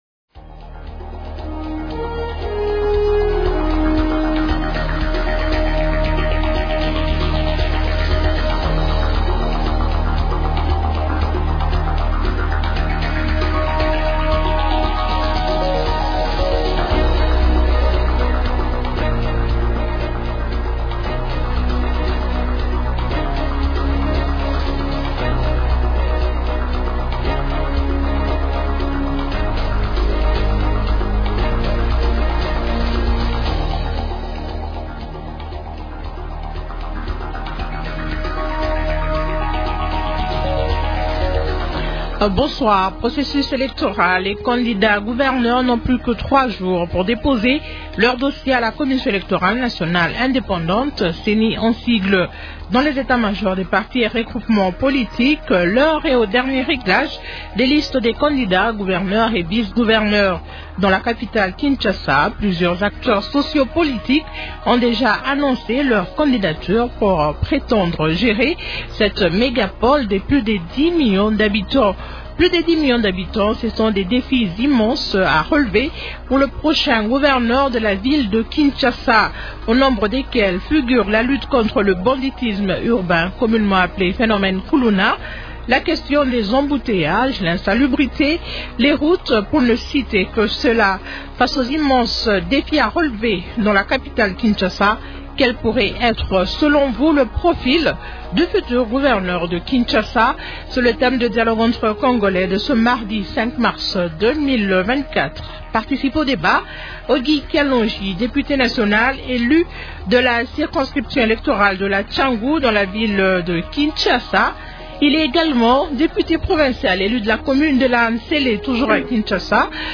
Question : -Face aux immenses défis à relever dans la capitale Kinshasa, quel pourrait être selon vous le profil du futur gouverneur de Kinshasa ? Invités : -Auguy Kalonji, député national élu de la circonscription électorale de la Tshangu dans la Ville de Kinshasa.